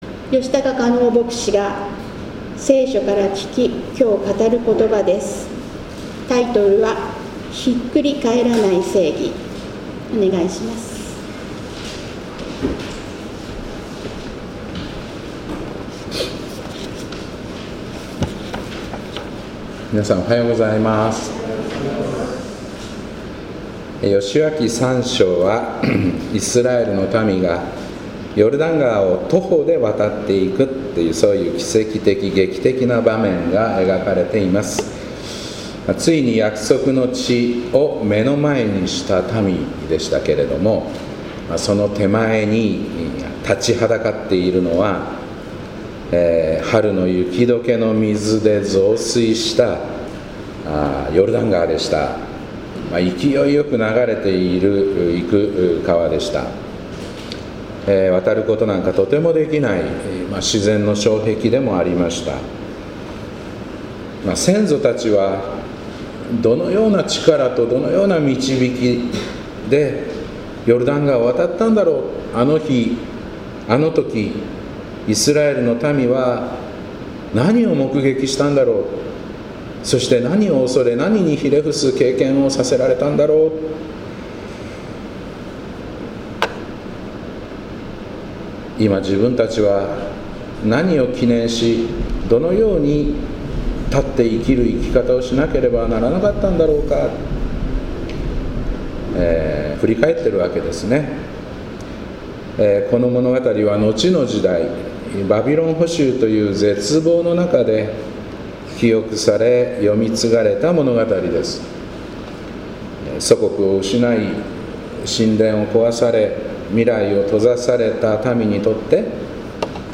2025年9月14日礼拝「ひっくり返らない正義」